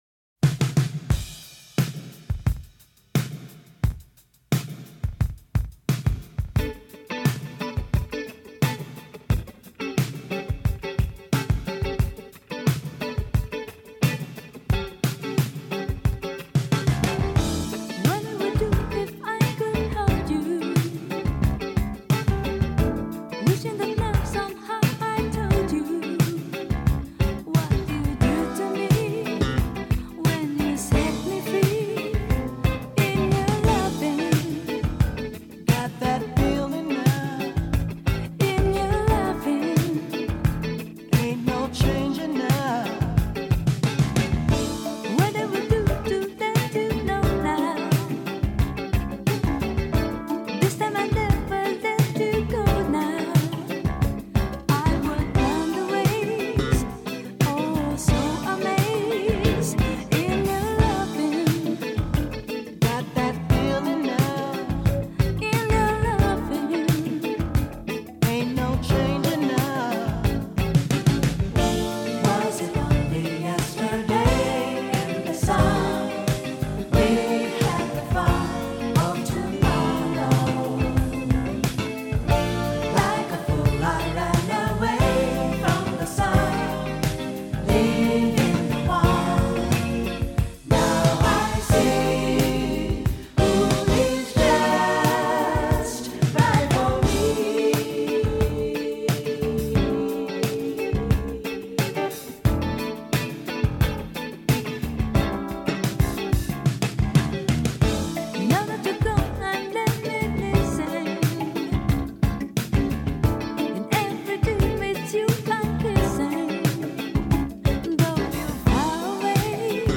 ジャンル(スタイル) JAPANESE POP CLASSIC